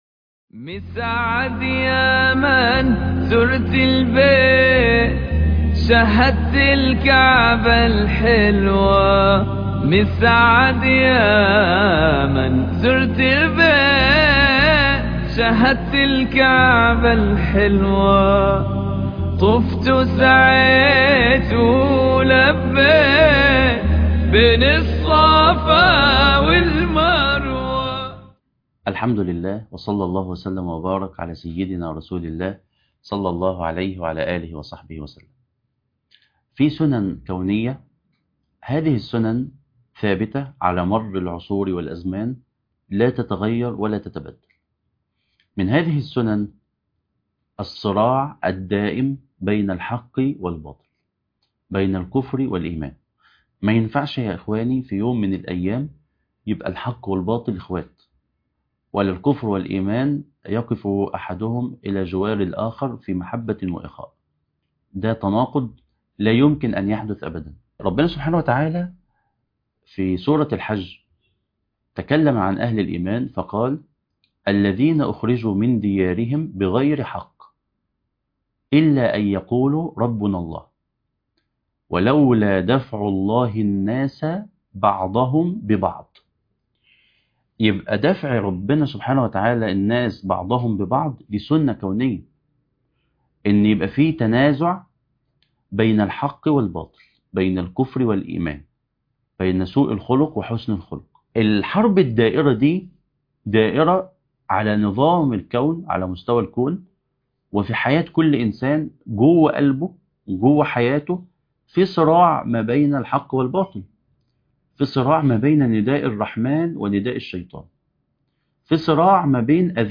درس عمدة الفقه (11) - تابع كتاب الطهارة